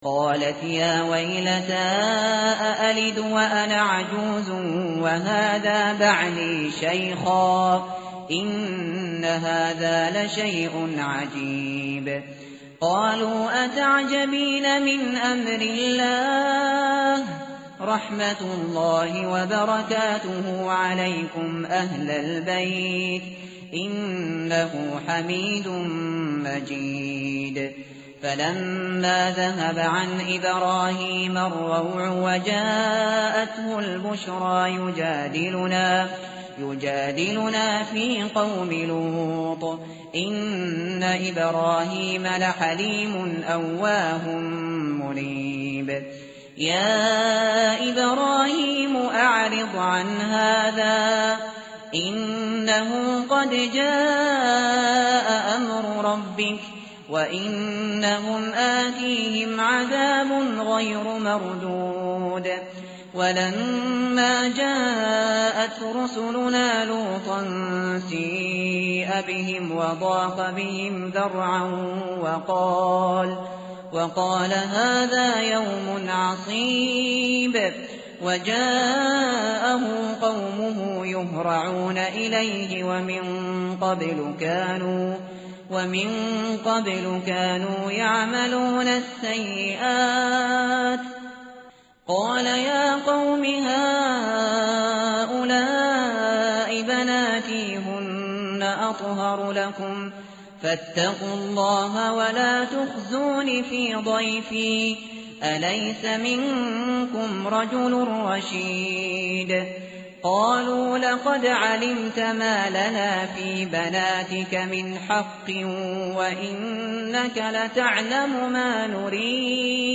tartil_shateri_page_230.mp3